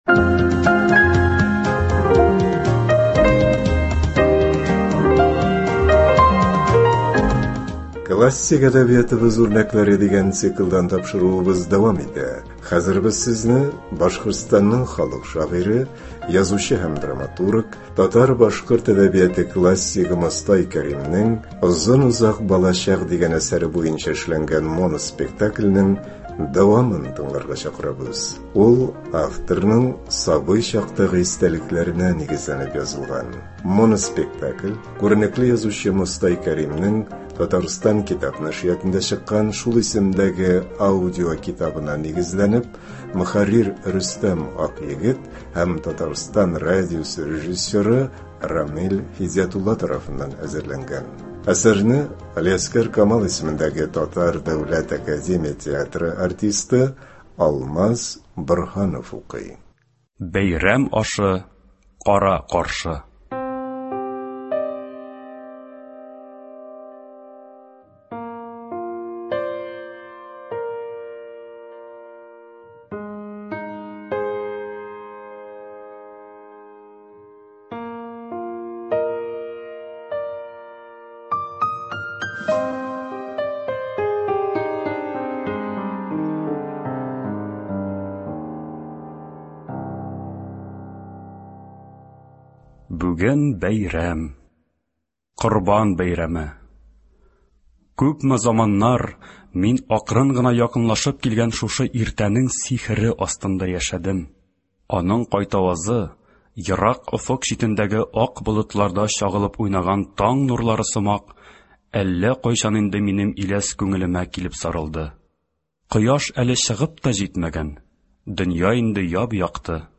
Мостай Кәрим. “Озын-озак балачак”. Моноспектакль.